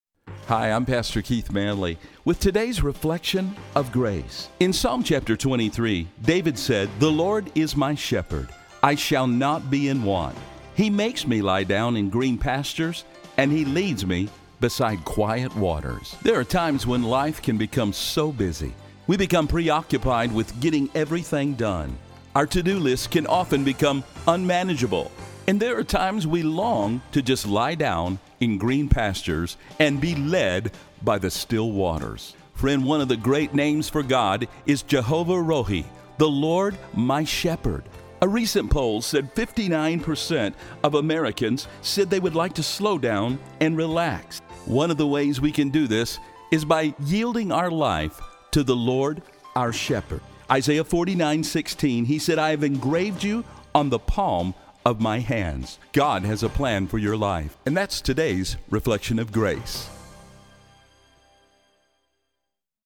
These spots air locally on 93.3 FM and on the Wilkins Radio Network heard in 27 Radio Stations around the country.